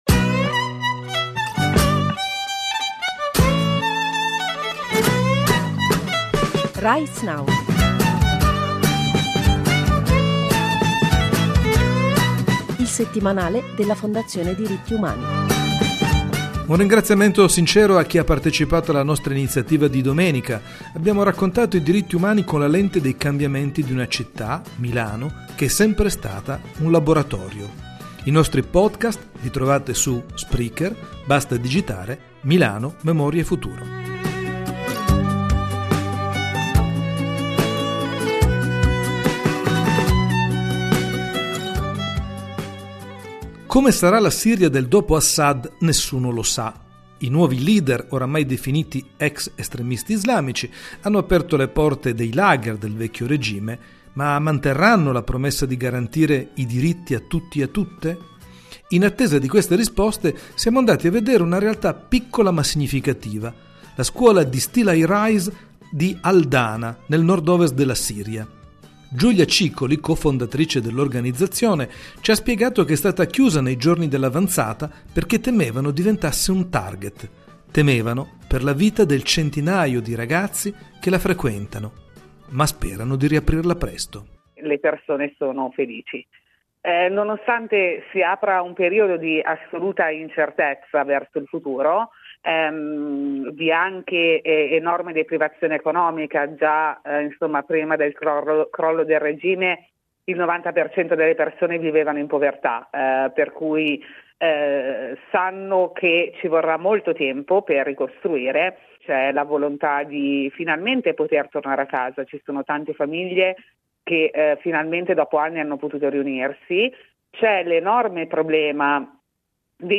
Sommario: - La nuova Siria vista con gli occhi dei bambini nati e vissuti in guerra. Intervista